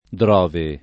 [ dr 0 ve ]